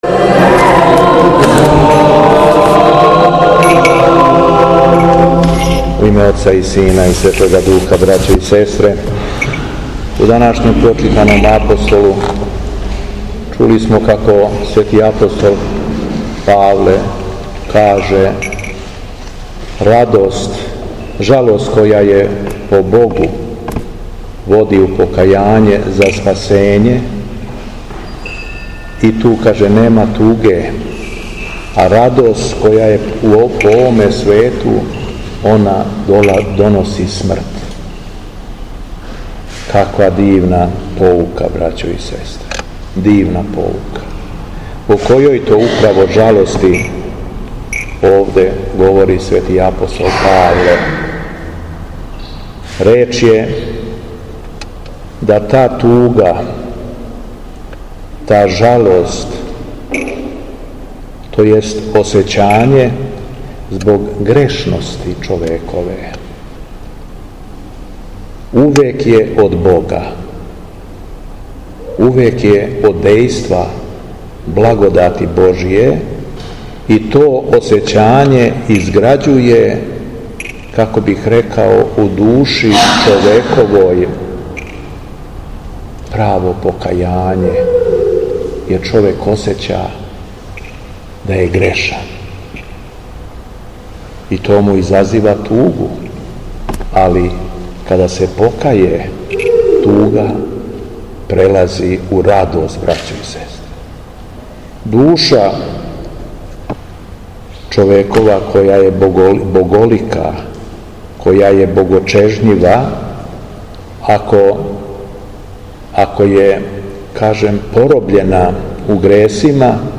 Беседа Његовог Преосвештенства Епископа шумадијског г. Јована
У препуном храму, по прочитаном Јеванђељу Преосвећени се обратио вернима надахнутом беседом о покајању и спасењу: